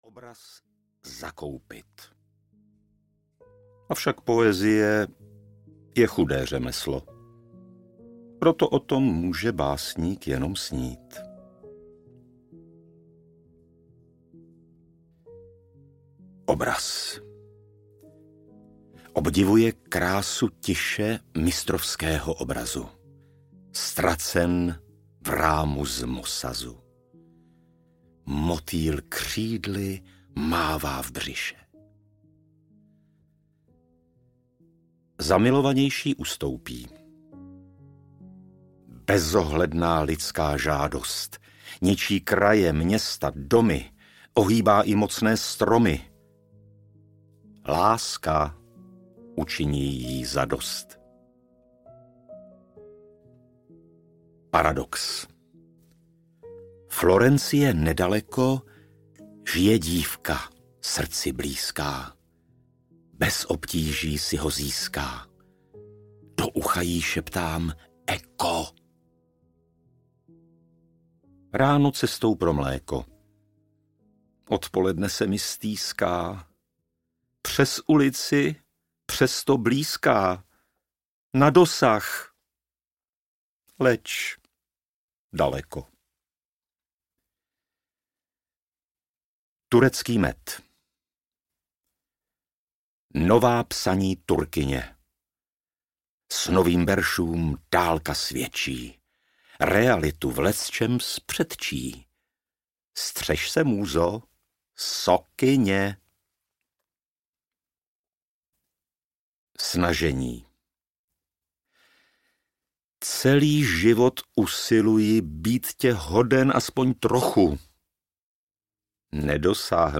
Antologie březnové múze audiokniha
Ukázka z knihy